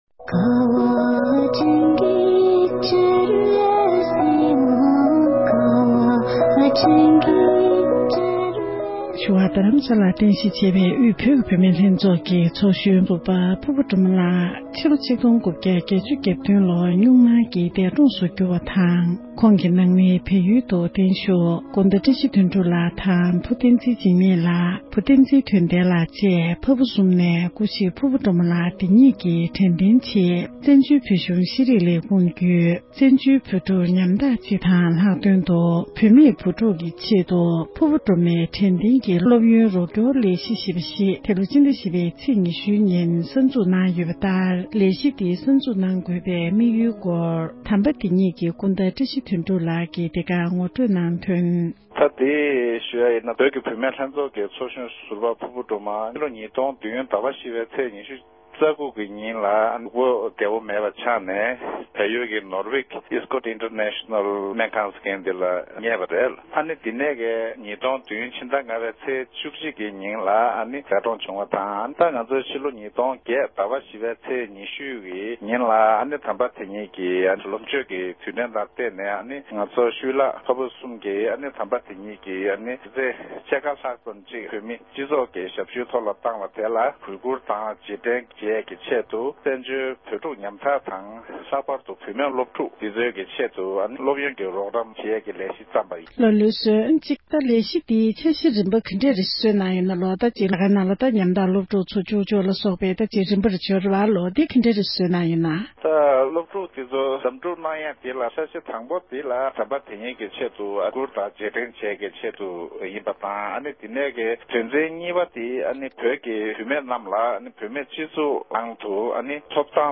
འབྲེལ་ཡོད་མི་སྣར་བཀའ་དྲི་ཞུས་པ་ཞིག